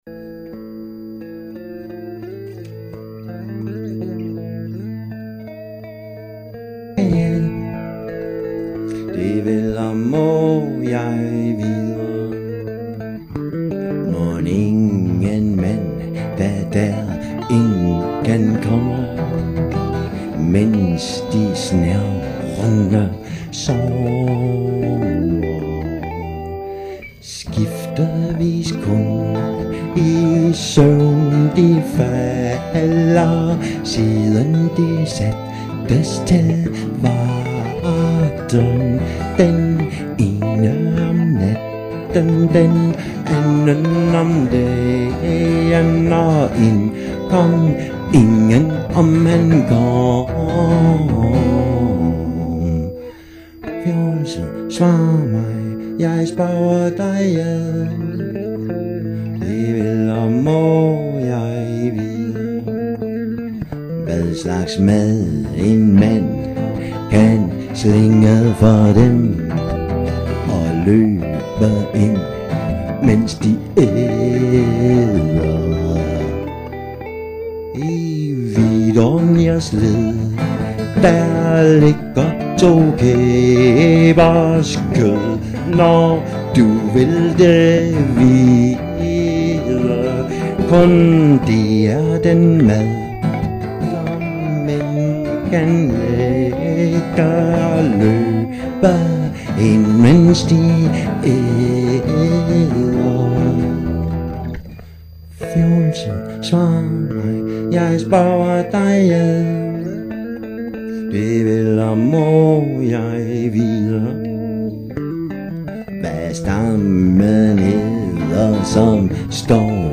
Eddagal demo vers 15-ud (Live radio)